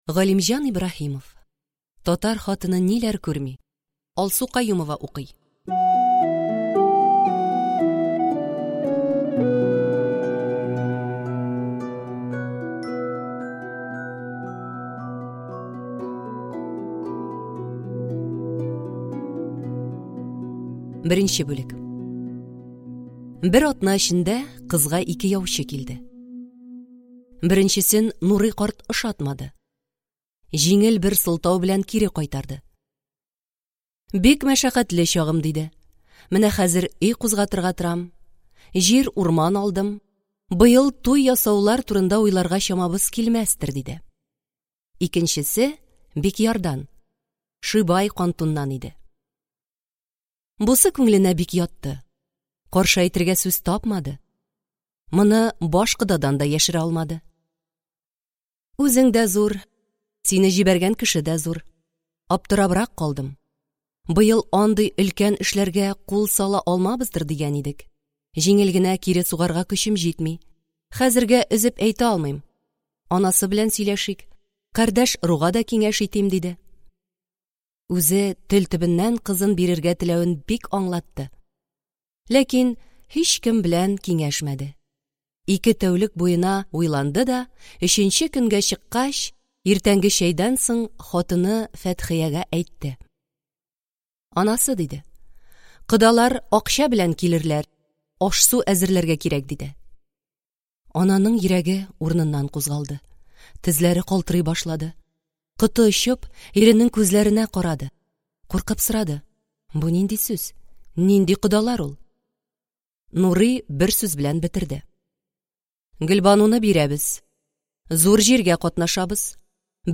Аудиокнига Татар хатыны ниләр күрми | Библиотека аудиокниг